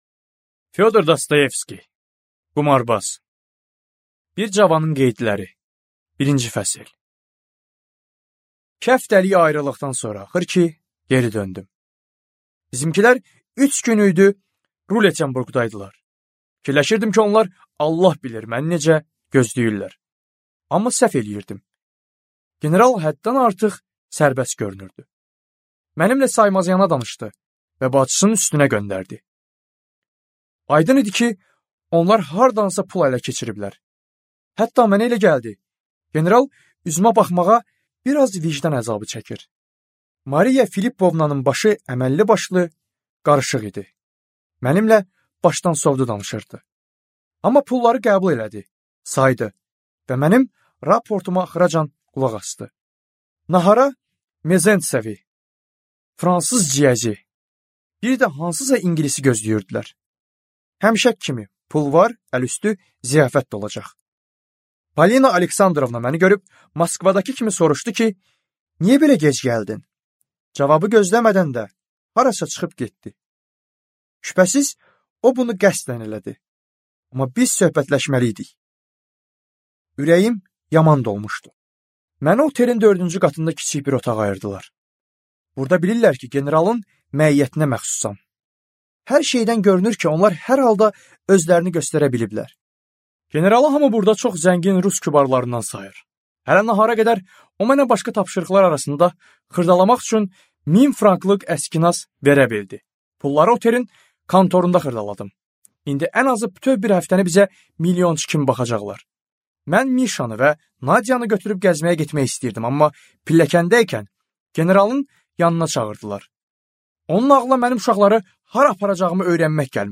Аудиокнига Qumarbaz | Библиотека аудиокниг